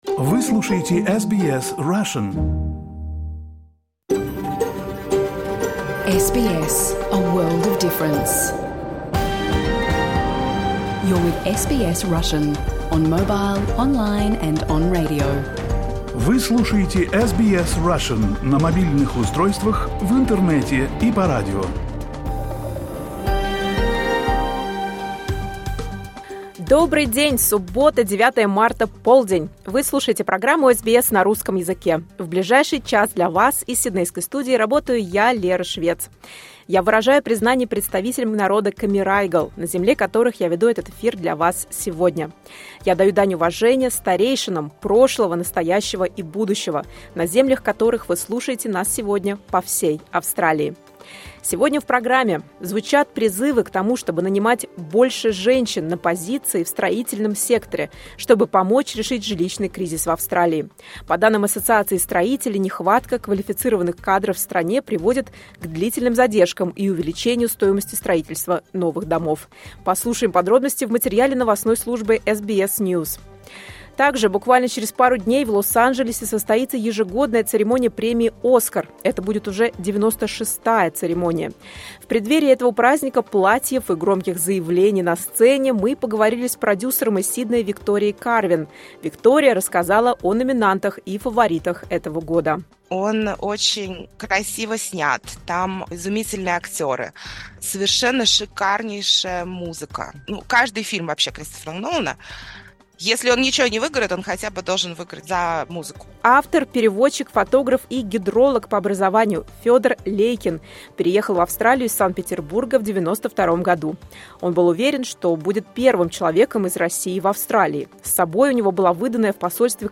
You can listen to SBS Russian program live on the radio, on our website and on the SBS Audio app.